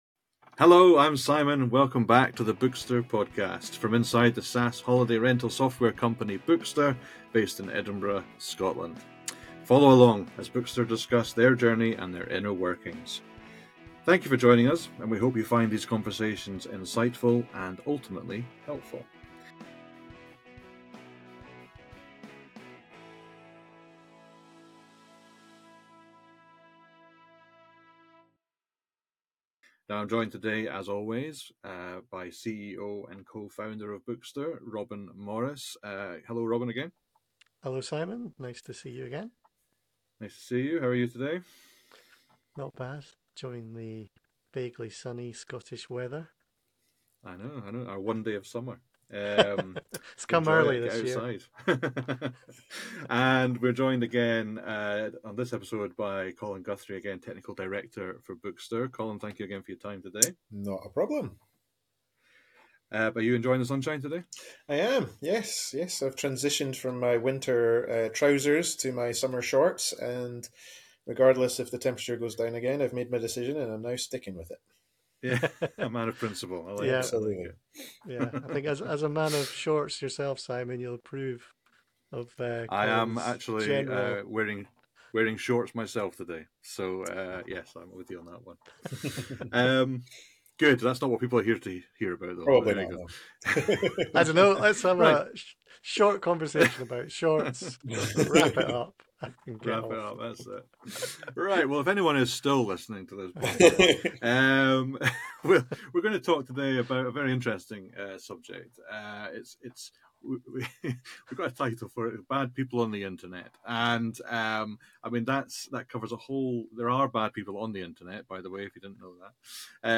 Whether you're a tech enthusiast or just curious about the behind-the-scenes workings of a SaaS company, this conversation promises to be both informative and engaging.